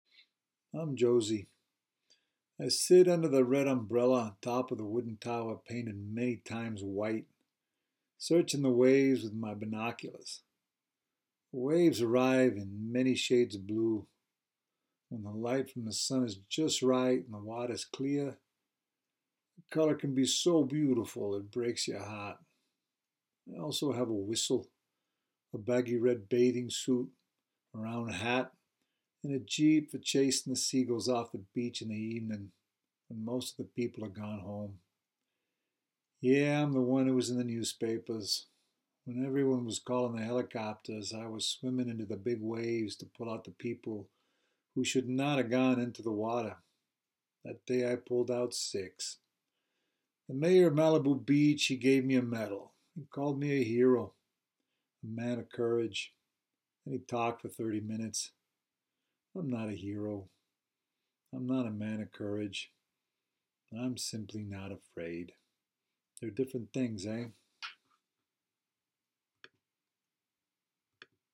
prologue-boston-soft.m4a